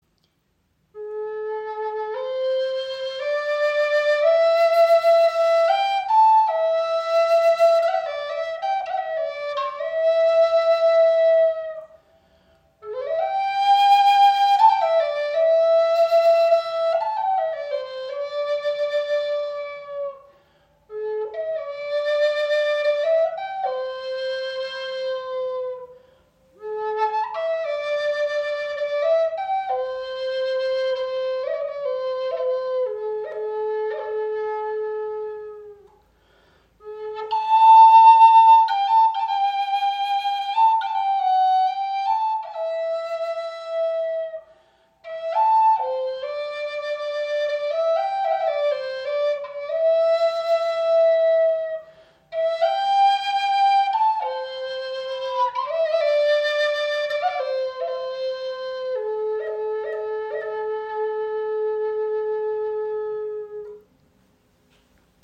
Diese Thunderbird-Flöte aus gemasertem Ahorn besticht durch ihre helle Maserung und klare Resonanz.
Die Flöte liegt angenehm in der Hand, spricht leicht an und entfaltet einen klaren, warmen und robusten Ton.
Harmonischer, klarer Klang!
In A-Moll gestimmt, entfaltet die Flöte einen klaren, vollen und lebendigen Ton, der durch Volumen und Präsenz besticht.